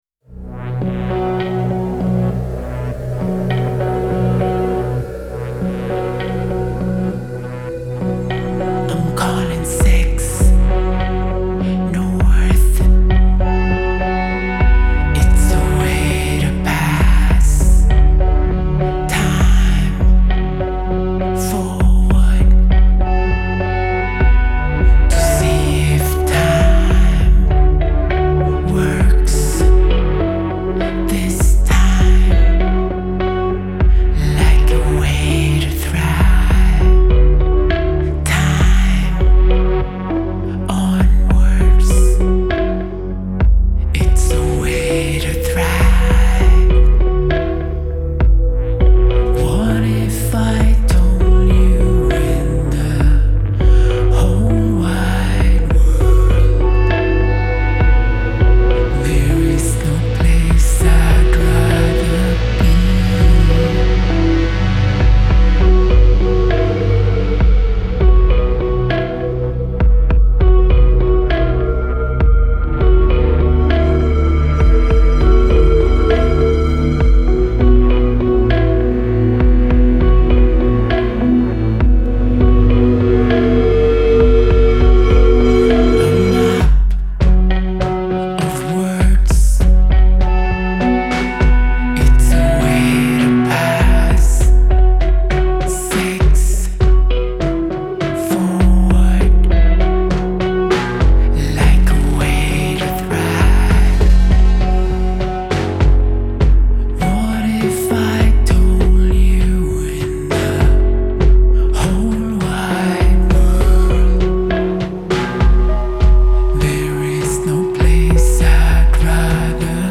Genre : Alternative & Indie